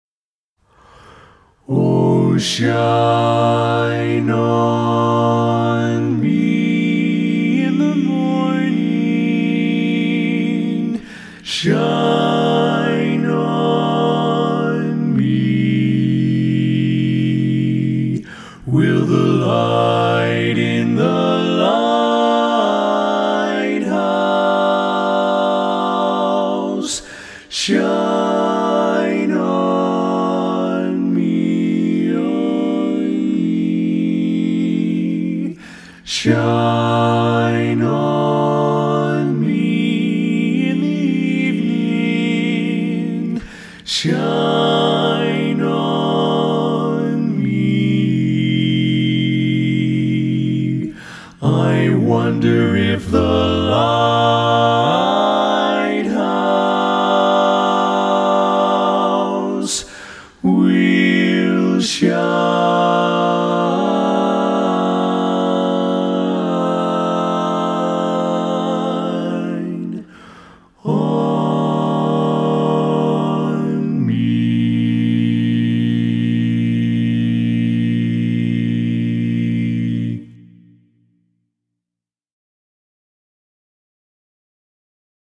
Barbershop
Full Mix